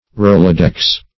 Rolodex \Rol"o*dex\ (r[^o]l"[-o]*d[e^]ks), a. [Trade Mark of